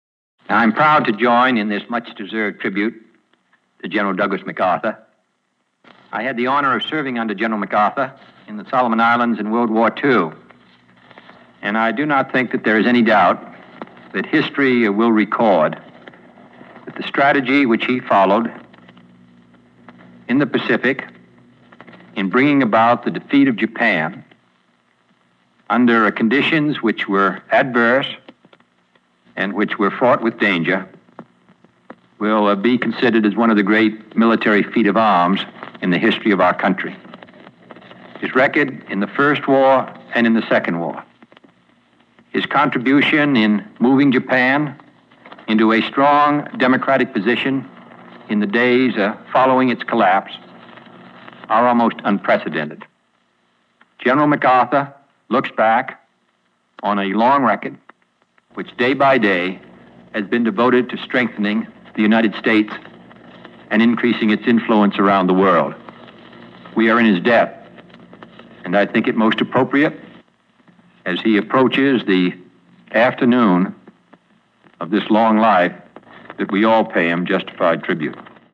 Audio mp3 of Address       Audio AR-XE mp3 of Address
AudioXE Note: Digitally enhanced and AI noise reduction technology used